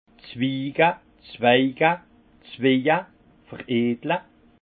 Haut Rhin zwige, zweige, zweje, veredle Français greffer
Ville Prononciation 68 Munster